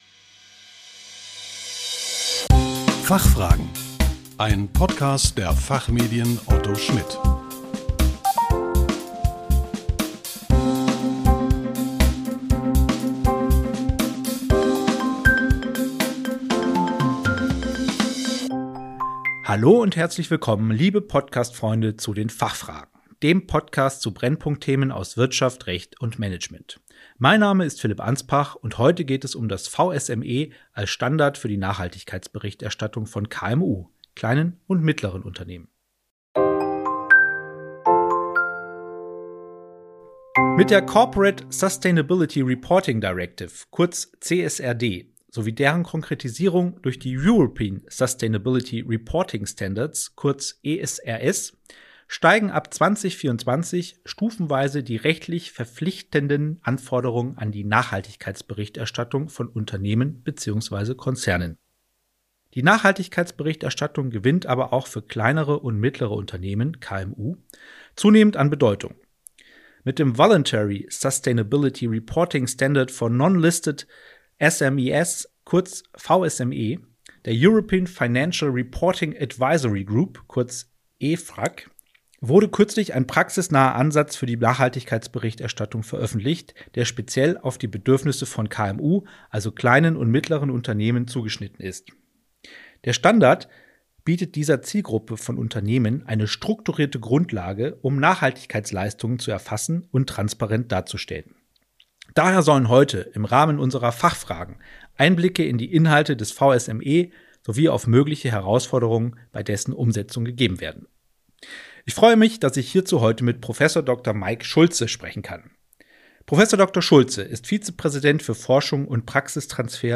Im Podcast der Fachmedien Otto Schmidt stellen wir ausgewiesenen Expert:innen je 5 Fragen zu aktuellen Brennpunkt-Themen aus Wirtschaft, Recht und Management. Einfach und kurz erklärt.